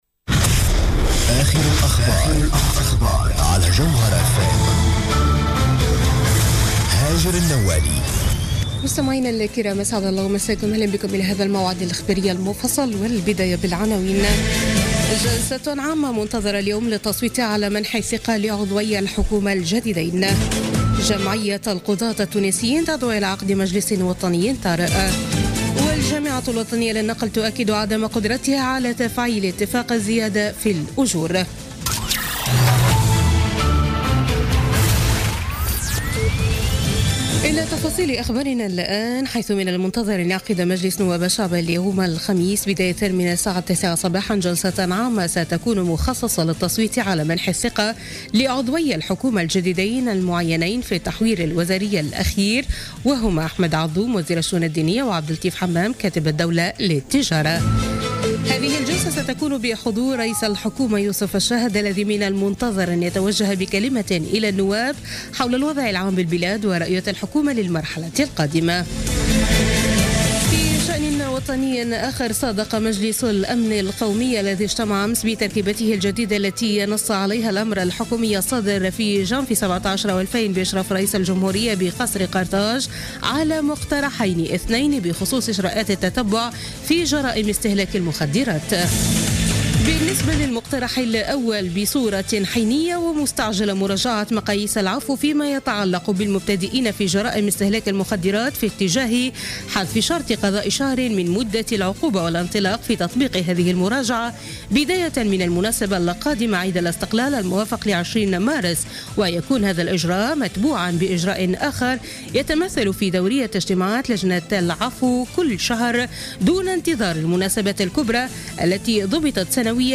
نشرة أخبار منتصف اليل ليوم الخميس 16 مارس 2017